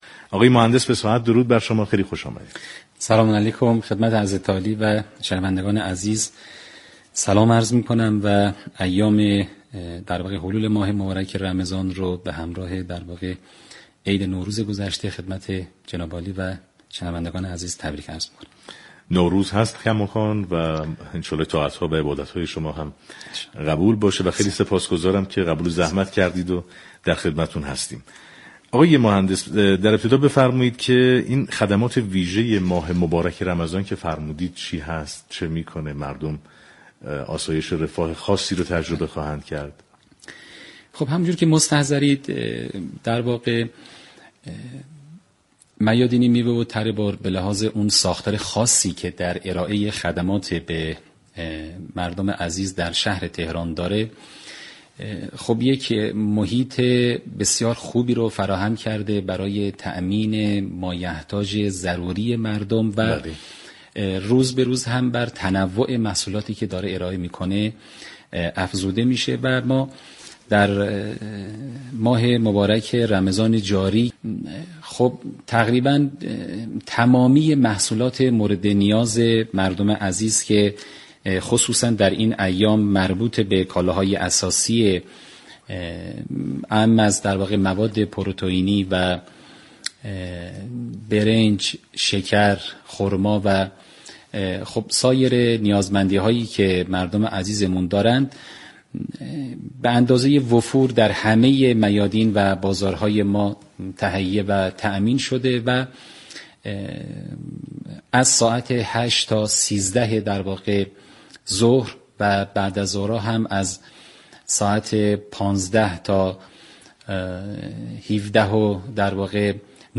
به گزارش پایگاه اطلاع رسانی رادیو تهران، ایوب فصاحت، مدیرعامل سازمان میادین تره بار شهرداری تهران در گفتگو با برنامه پل مدیریت رادیو تهران درباره خدمات ویژه سازمان میادین تره بار در ماه مبارك رمضان گفت: این سازمان محیط مناسبی را برای تامین مایحتاج ضروری مردم فراهم كرده و روز به روز هم بر تنوع محصولات آن افزوده می‌شود.